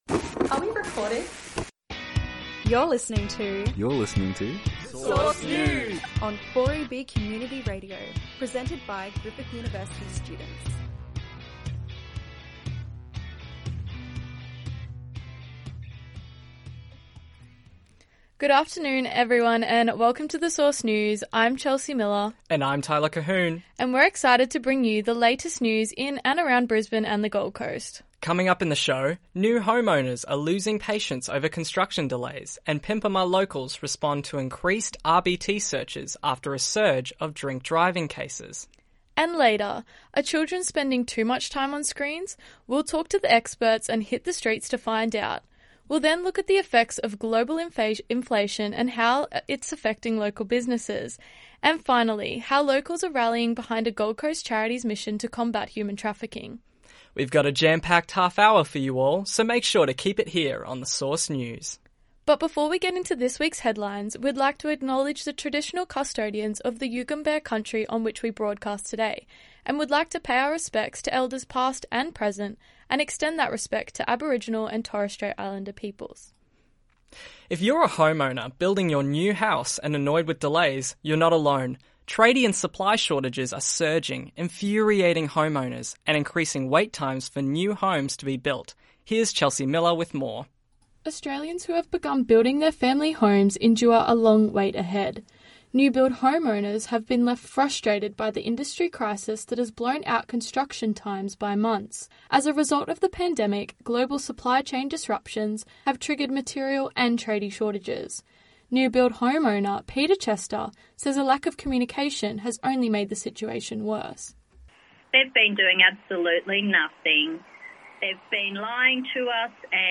The Source News is broadcast seasonally on Tuesdays and Thursdays at 5.04pm on 4EB Global Digital.